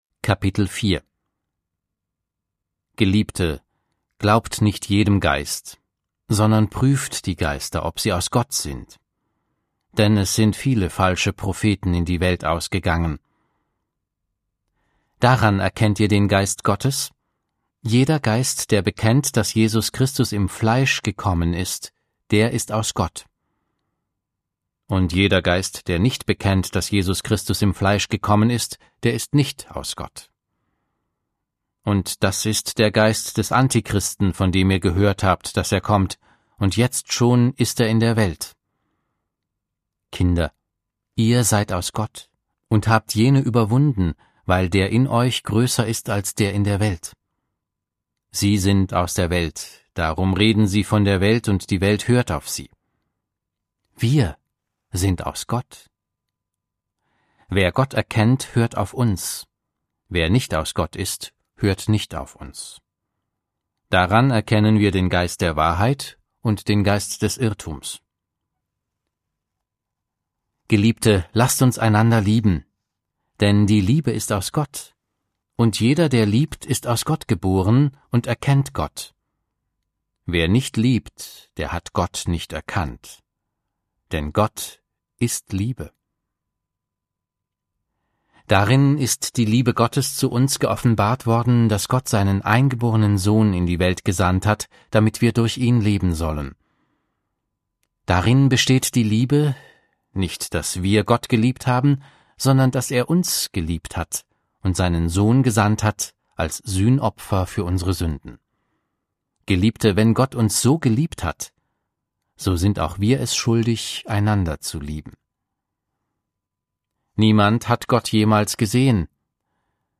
Audio Bibel - Schlachter 2000 der 1.